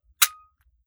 9mm Micro Pistol - Dry Trigger 003.wav